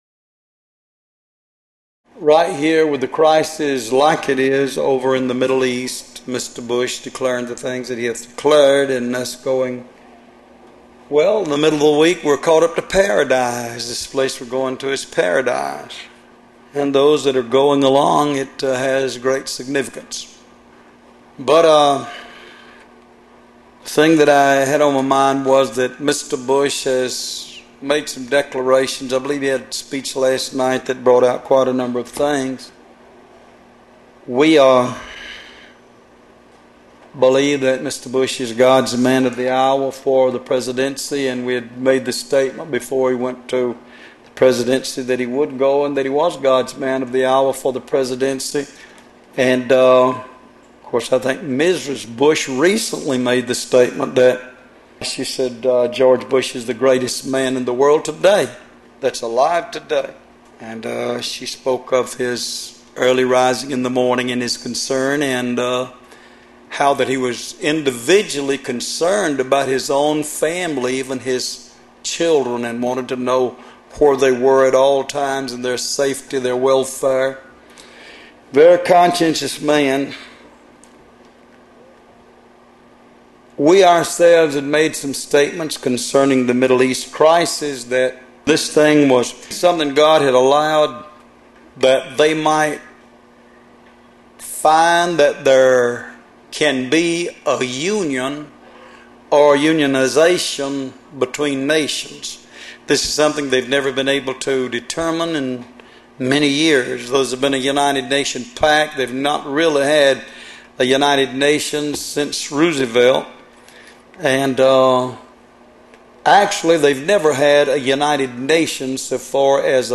Location: Love’s Temple in Monroe, GA USA